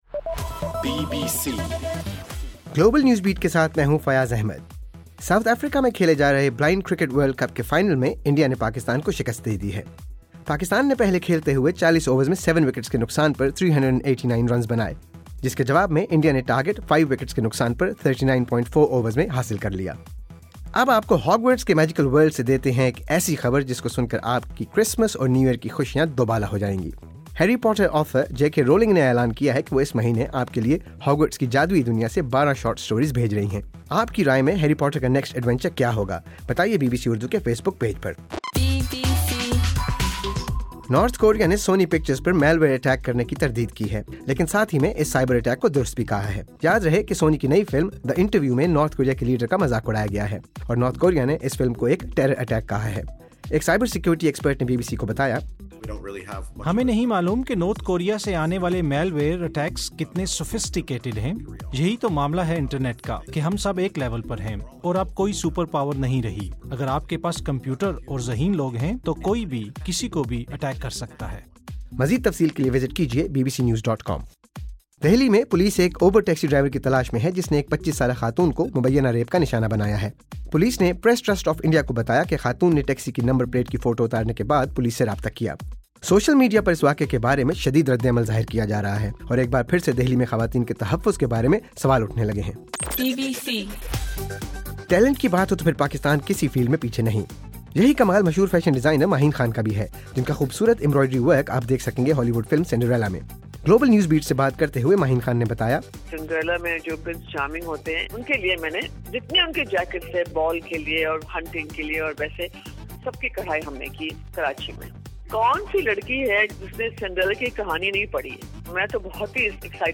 دسمبر 7: رات 12 بجے کا گلوبل نیوز بیٹ بُلیٹن